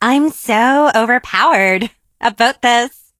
emz_start_vo_03.ogg